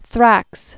(thrăks) fl. 100 BC.